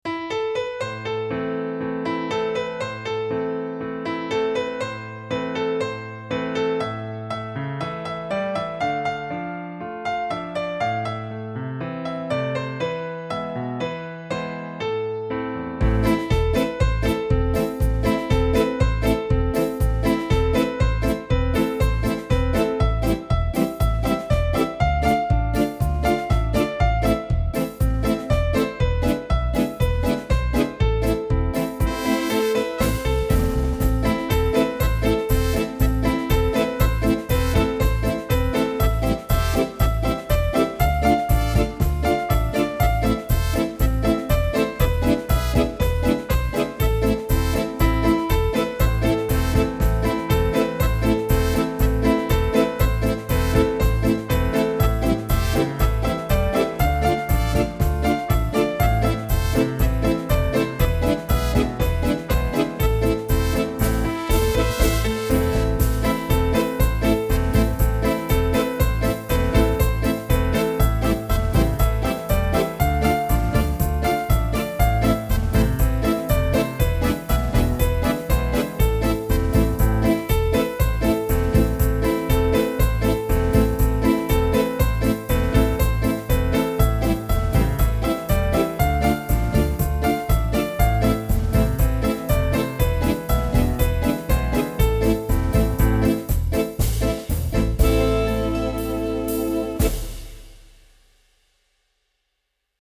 ITALIA---bella-ciao-italian-partisan-folk-song-from-the-late-19th-century-3052.mp3